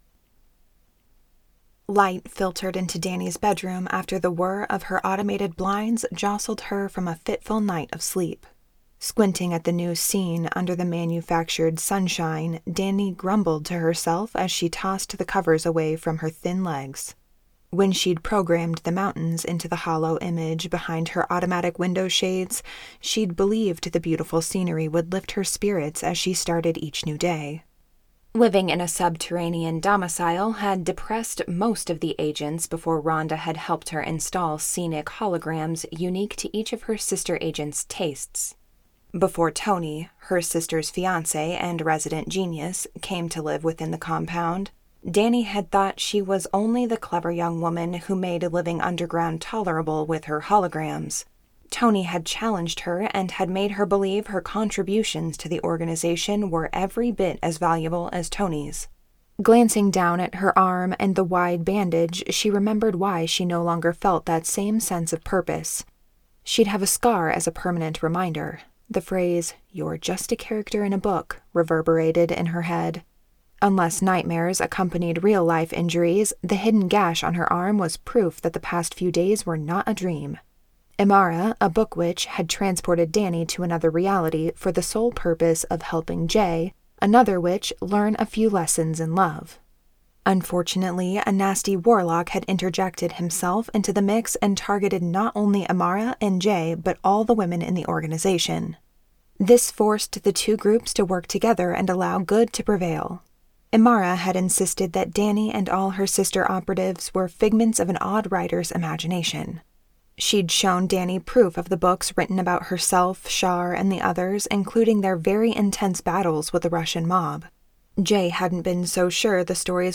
Compound Interest by Annette Mori [Audiobook]